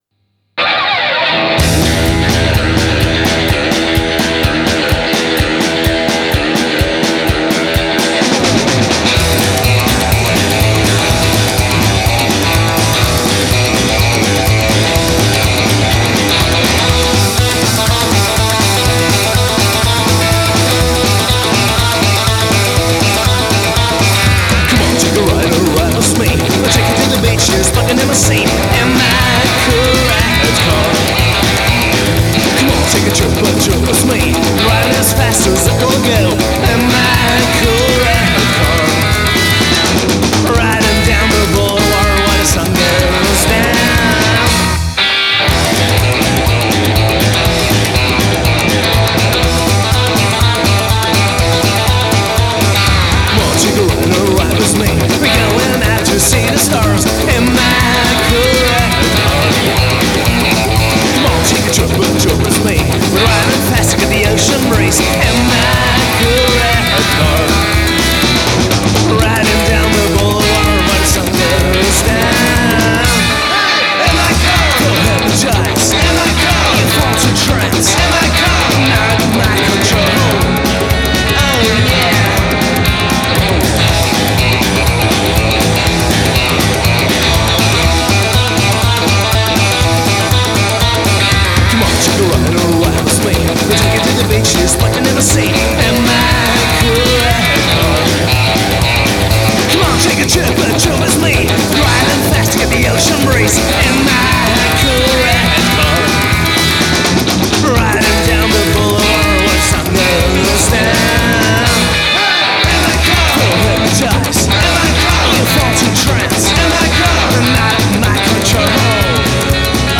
Great Psychobilly